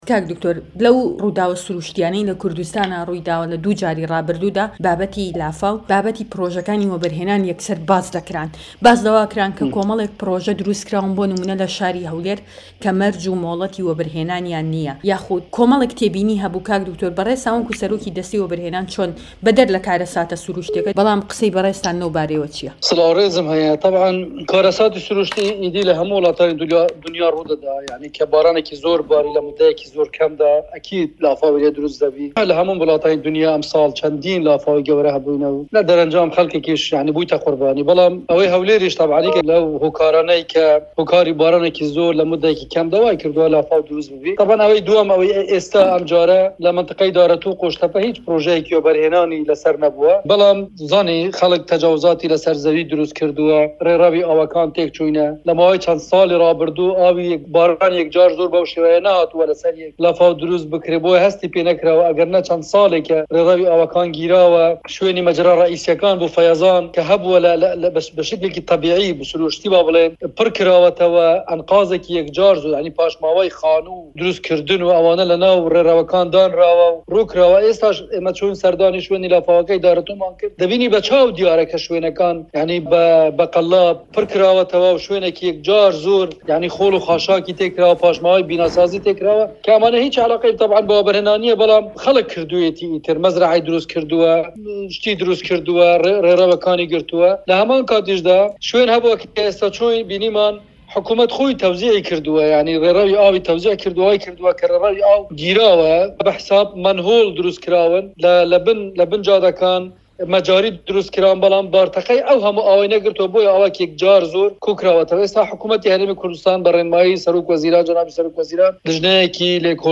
دەقی وتووێژەکە